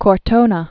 (kôr-tōnə, -nä), Pietro Berrettini da 1596-1669.